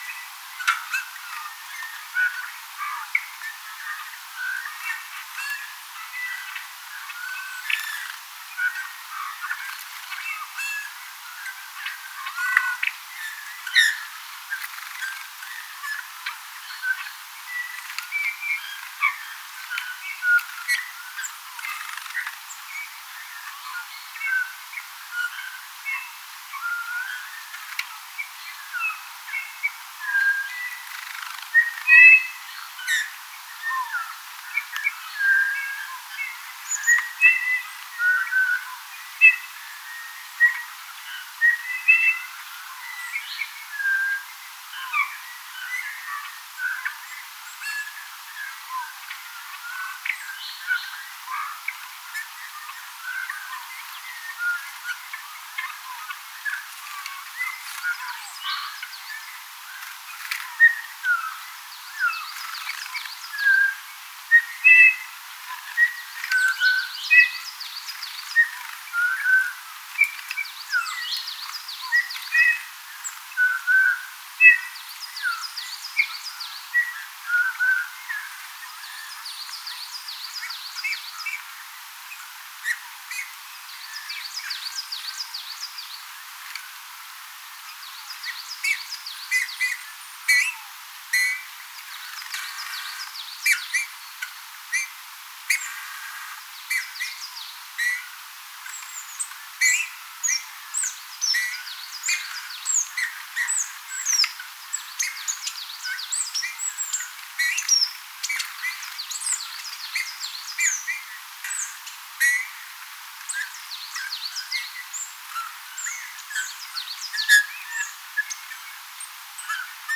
Naaraan laulu koiraalle on pehmeämpää
ja hiljaisempaa kuin koiraan laulu.
Koiraan laulu on siihen verrattuna karkeampaa.
kaksi punatulkkua laulaa,
erikoisia pieniä huudahduksia laulun välissä
punatulkun_laulua_kuuluu_valilla_erikoisia_kuin_pienia_huudahduksia_ilm_kaksi_lintua_laulaa.mp3